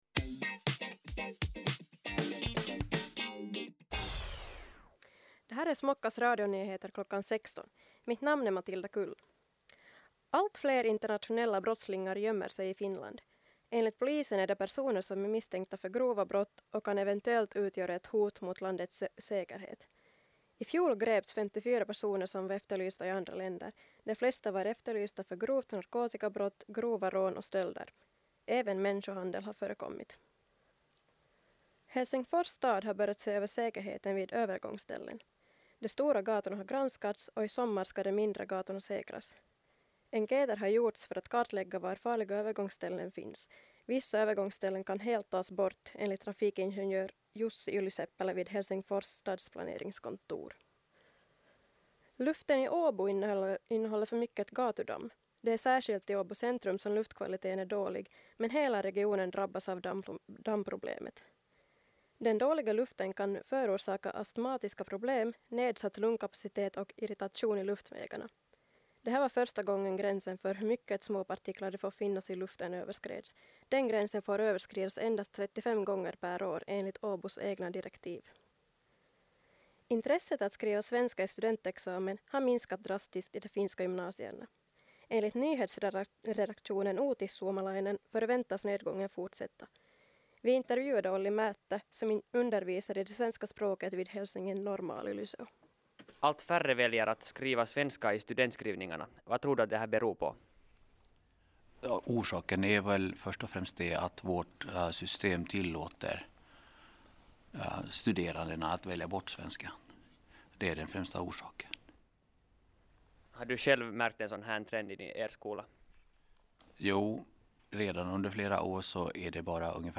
Radionyheter kl. 16